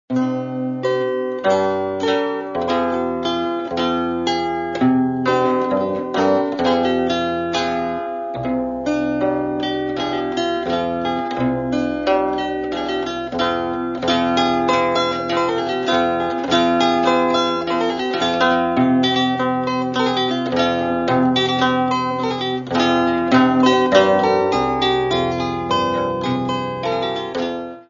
Catalogue -> Folk -> Bandura, Kobza etc